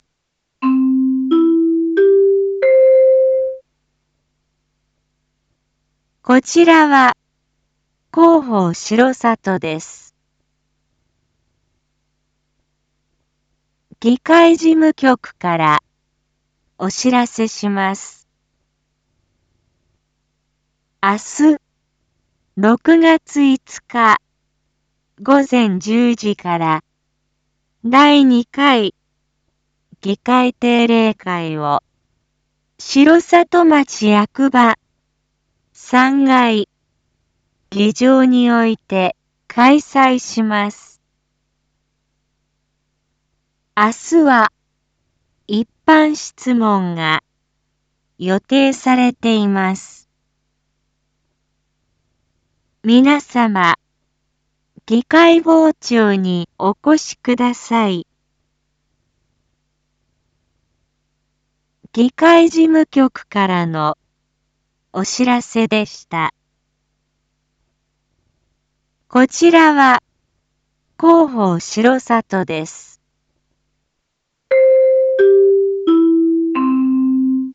一般放送情報
Back Home 一般放送情報 音声放送 再生 一般放送情報 登録日時：2025-06-04 19:01:19 タイトル：第２回議会定例会⑤ インフォメーション：こちらは広報しろさとです。